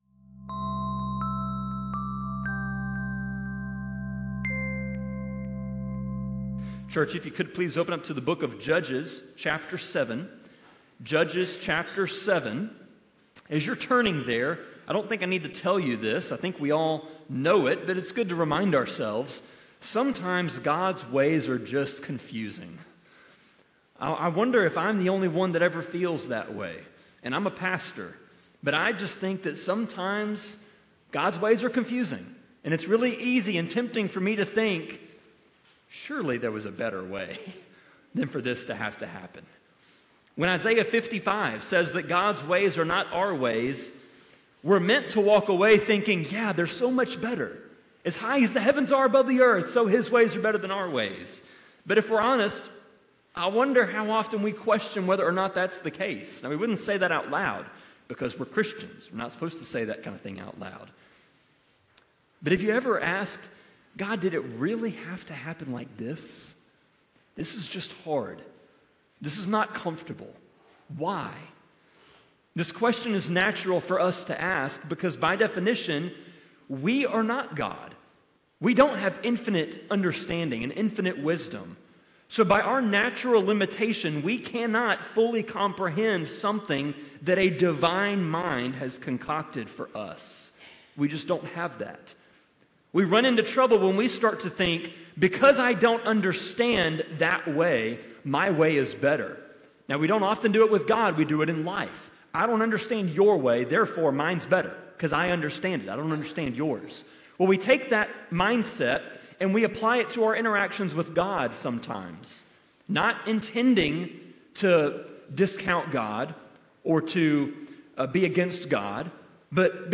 Sermon-25.11.2-CD.mp3